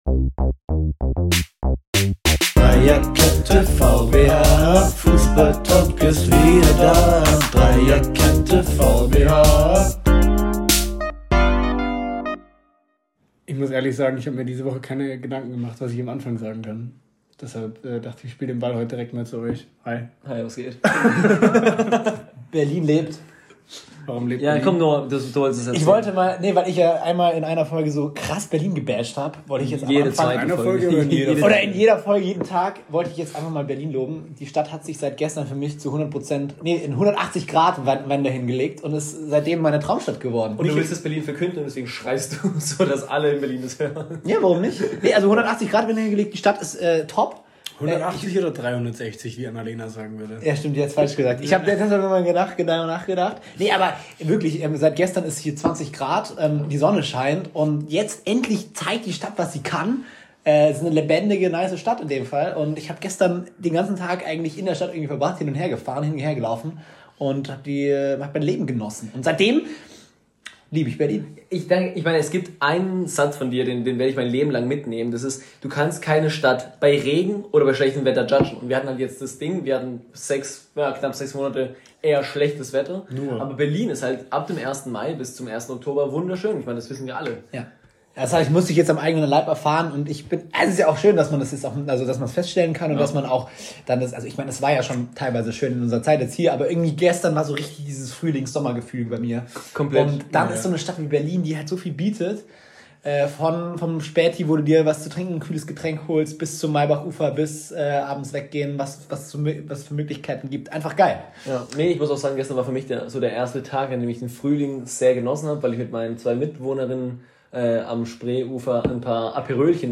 Lehnt euch einfach zurück und lasst die Meinungen dreier absoluten Experten euren Alltag bereichern.